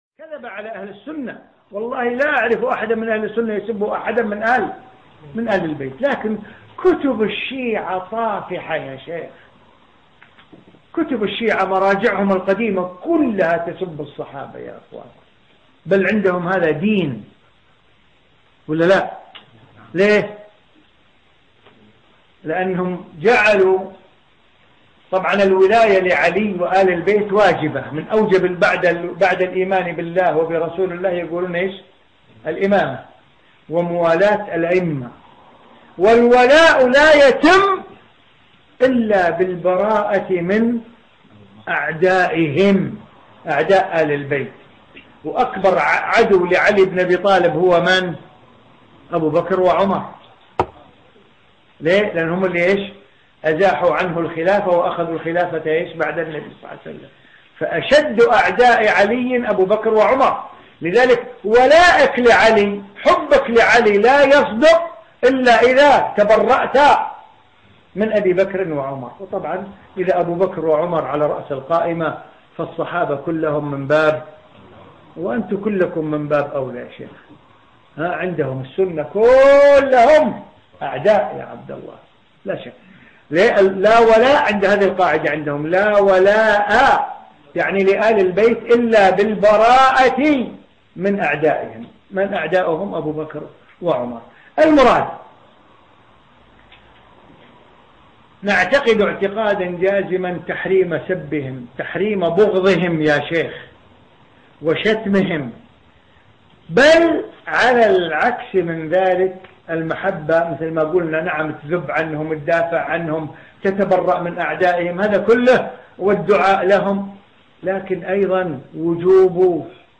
في مسجد الصحابة السلفي في مدينة برمنجهام البريطانية في 19 شوال 1435